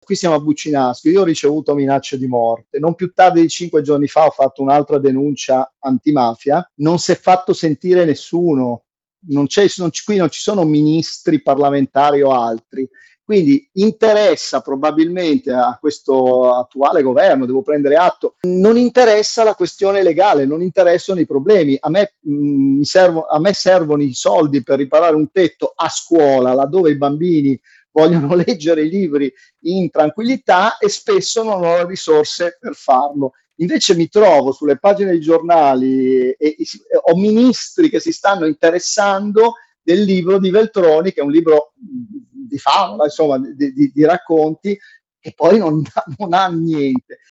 Sentiamo ancora il sindaco di Buccinasco Rino Pruiti.